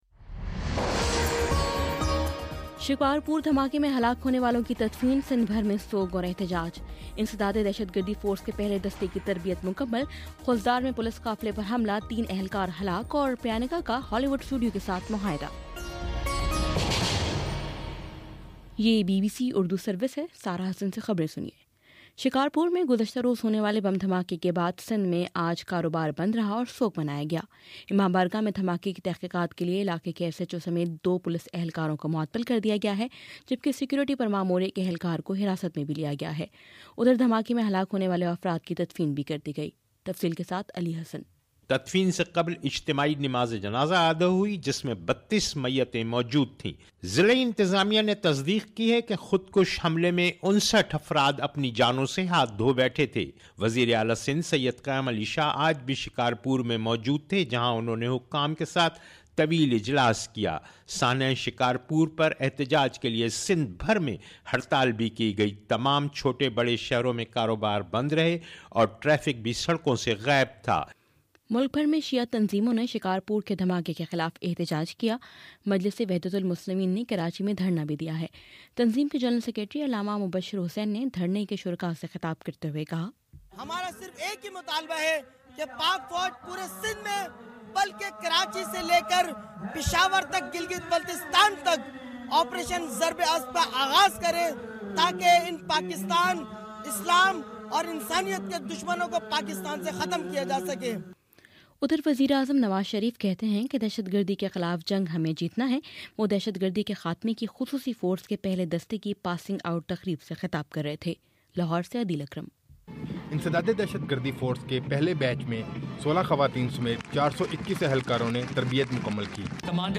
جنوری31: شام سات بجے کا نیوز بُلیٹن